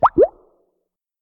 pop.mp3